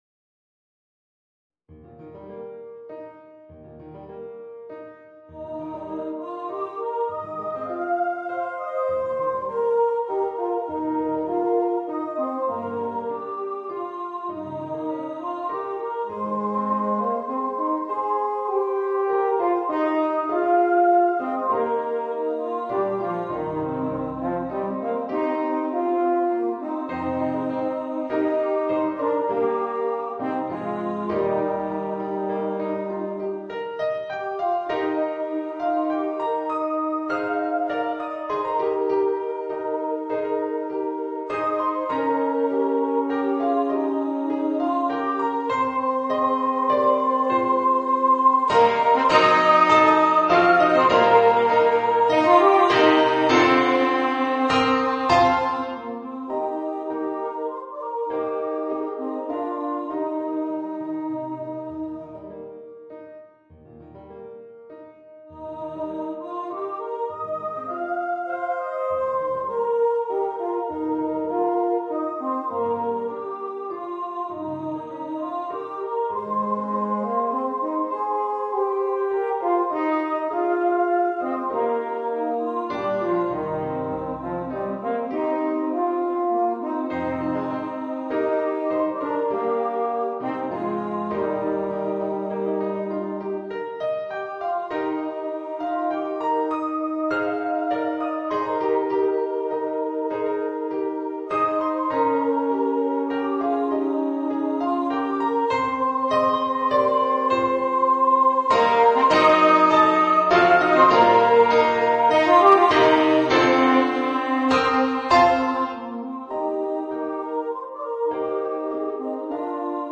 Voicing: Horn, Soprano Voice and Piano